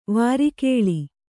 ♪ vāri kēḷi